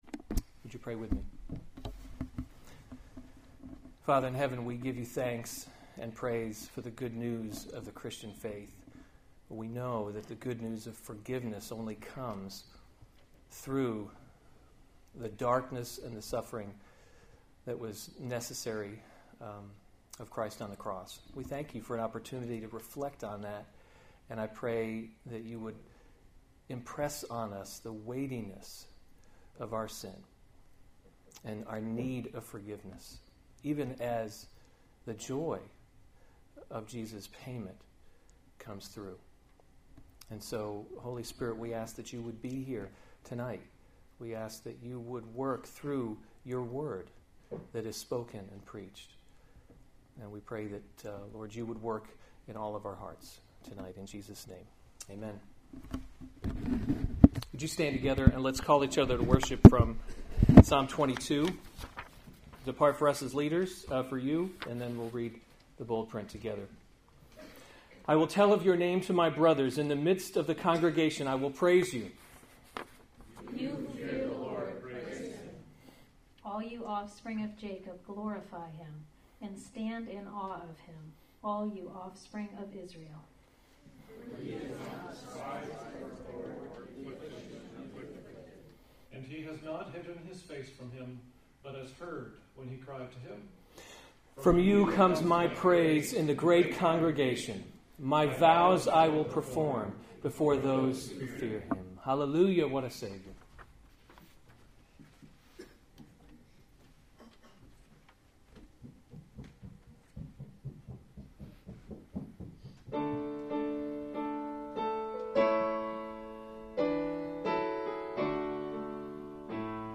Good Friday Tenebrae Service, Part 1 • Church of the Redeemer Manchester New Hampshire